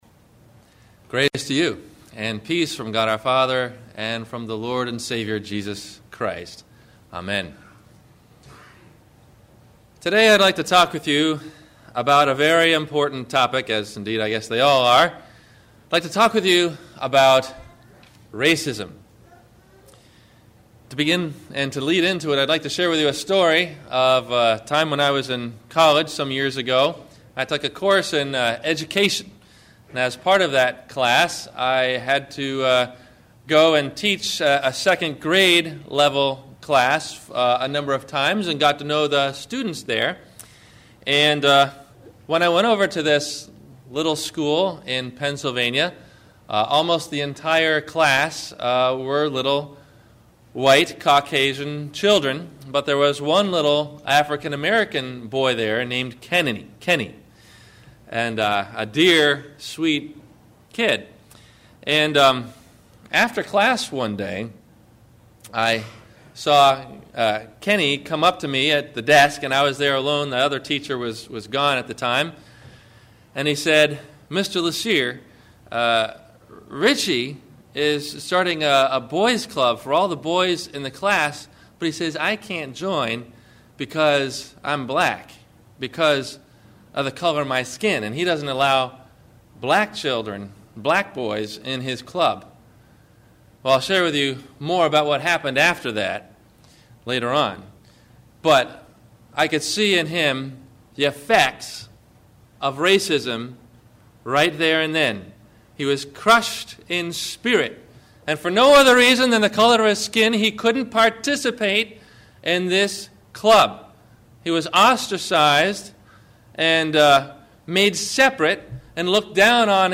Racism – Sermon – May 25 2008